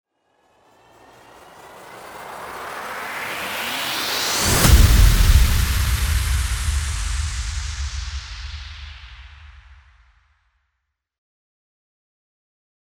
FX-1539-RISING-IMPACT
FX-1539-RISING-IMPACT.mp3